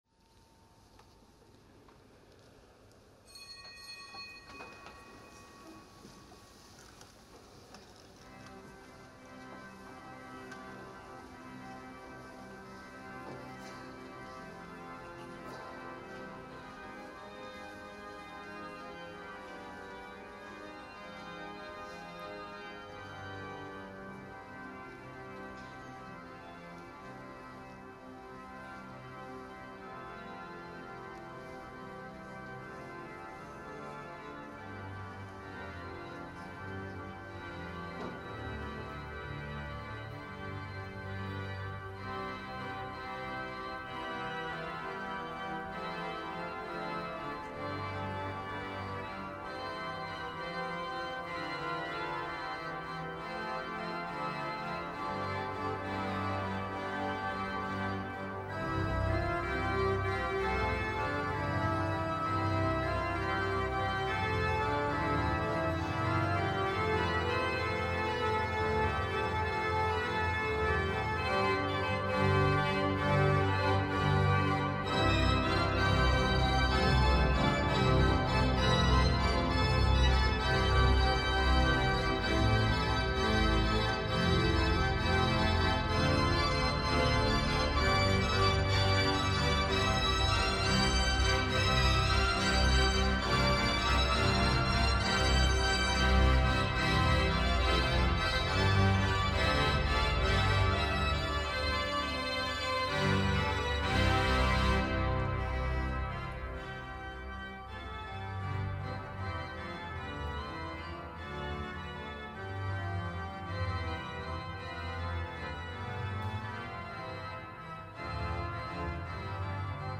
Pontifikalamt am Fest der Darstellung des Herrn
Erzbischof Rainer Maria Kardinal Woelki feierte das Pontifikalamt im Kölner Dom am Fest der Darstellung des Herrn.